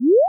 tap_card.wav